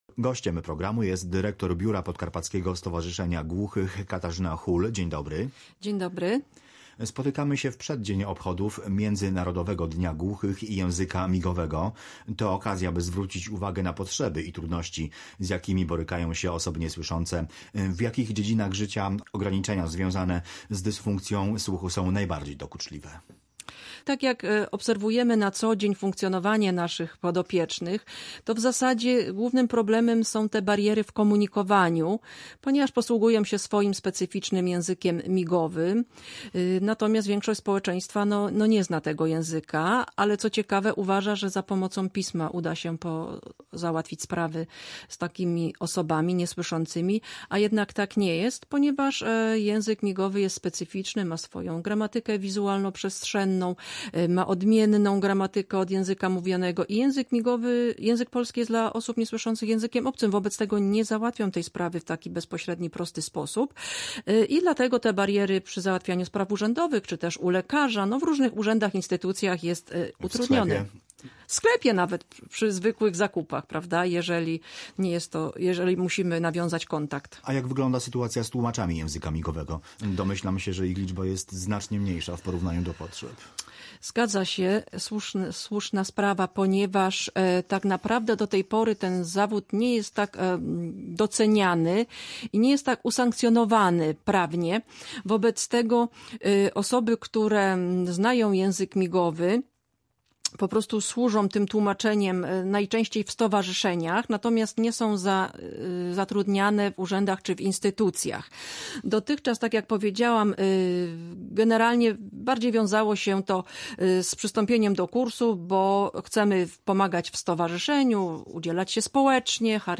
GOŚĆ DNIA. Brakuje tłumaczy języka migowego